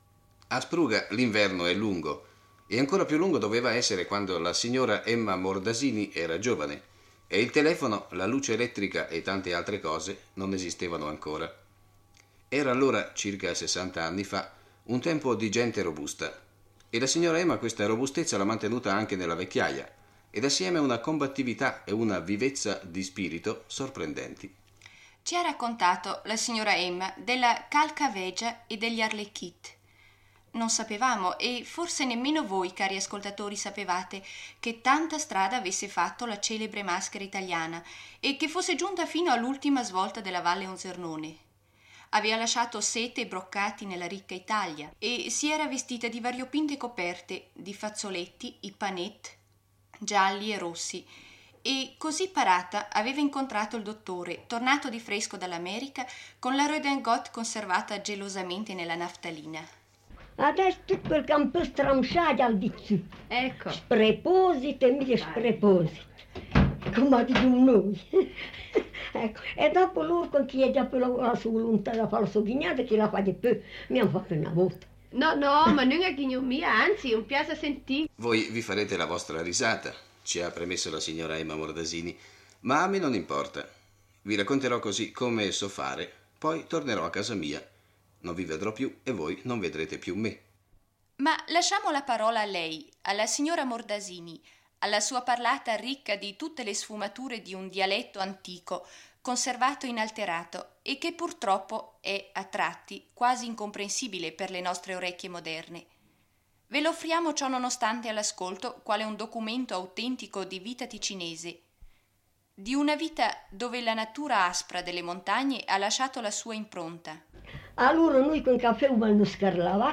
Con una parlata ricca delle sfumature di un dialetto antico